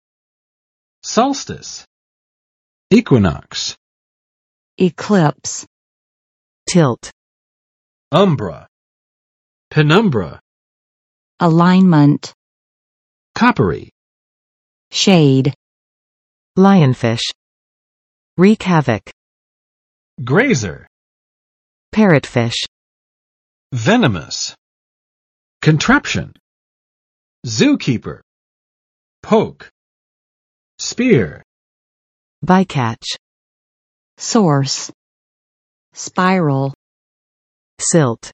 [ˋsɑlstɪs] n.【天】至；至点